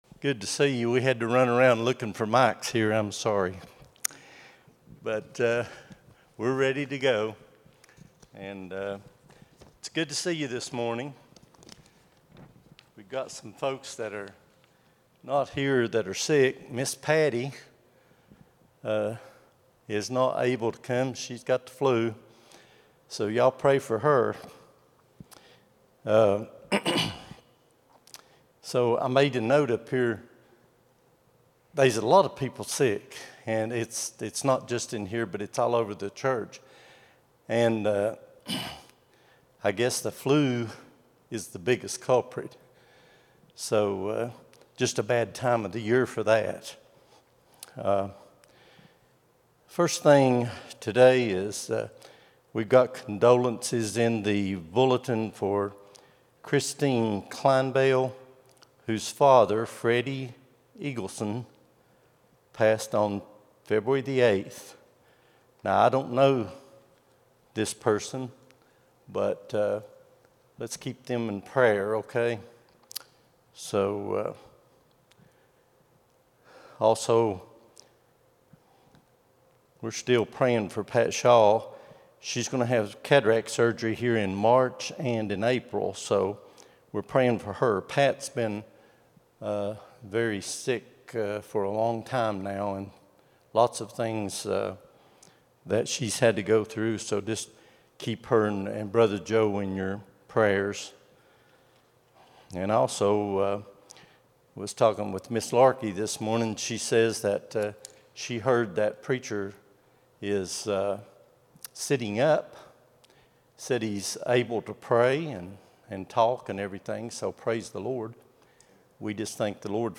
02-16-25 Sunday School | Buffalo Ridge Baptist Church